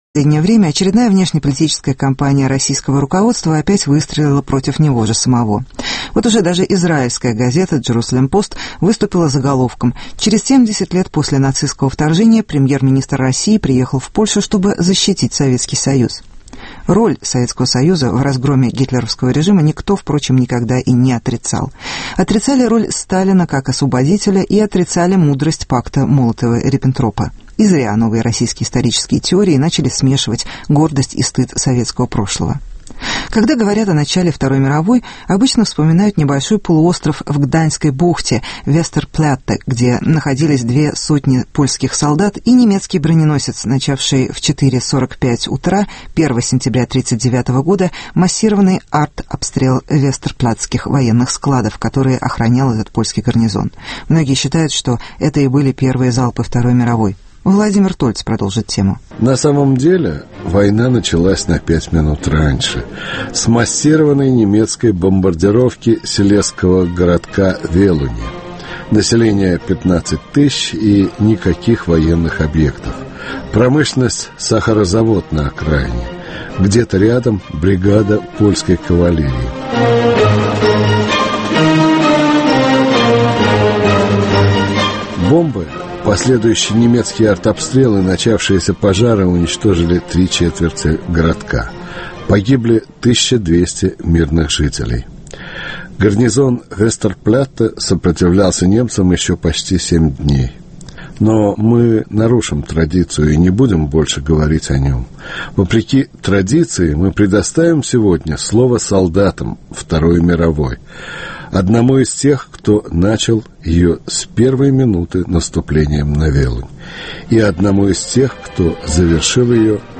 70 лет с начала Второй мировой. Вспоминают советский и немецкий солдаты